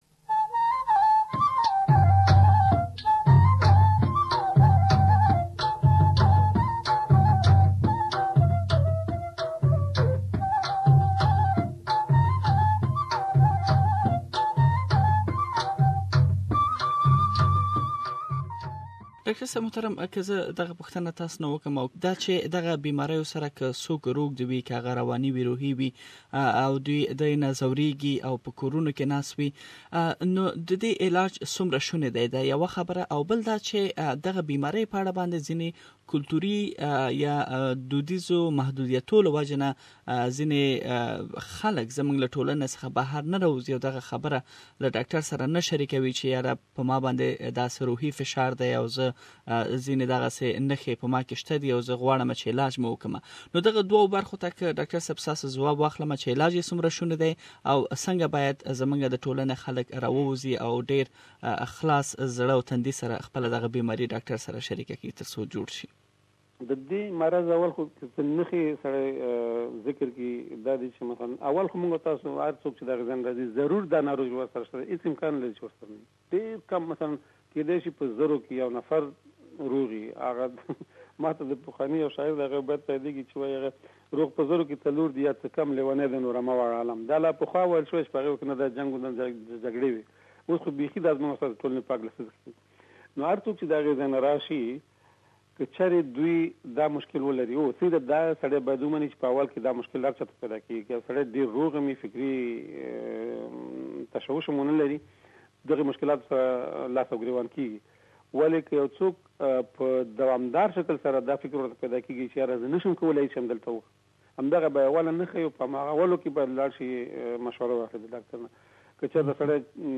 مرکه کړې چې تاسې د ښاغلي د مرکې لومړۍ برخه دلته اوريدلی شئ.